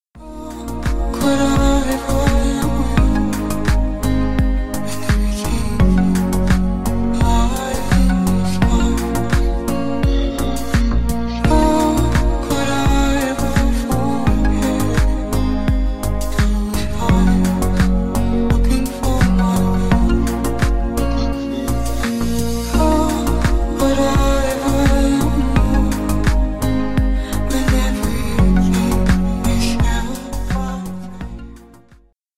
Ремикс # Танцевальные
грустные